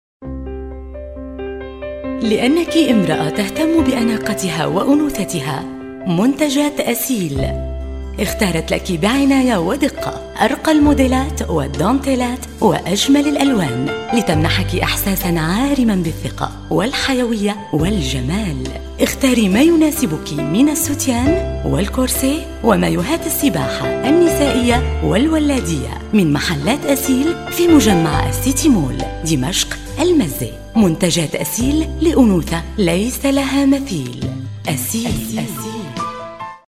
Voice Description: Real Perky Warm Sexy Young Mom Energetic Attitude Articulate Smart IN ONE WORD : ( Professional )
Arabic female voice over, UAE voice over, Professional female voiceover artist, voice over talent, Arabic voice over
Sprechprobe: Werbung (Muttersprache):